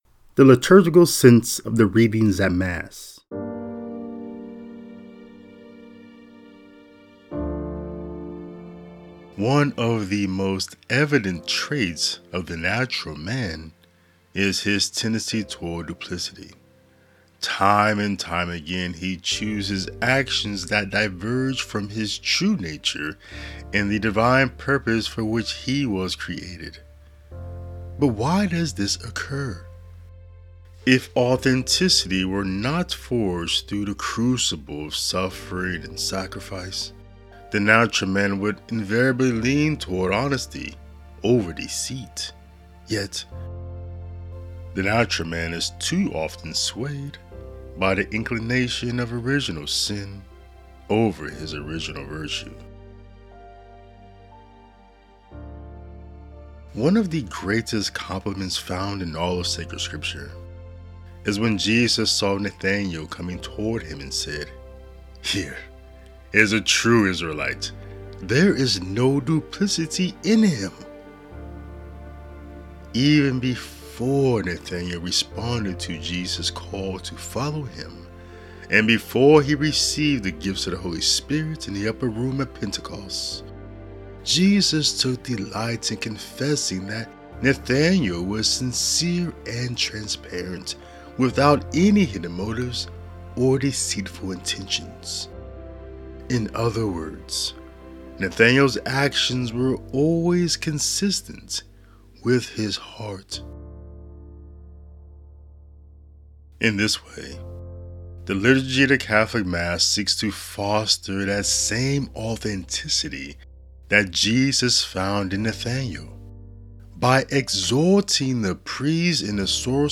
A Commentary and Reflection on the Readings for the Eighth Sunday in Ordinary Time.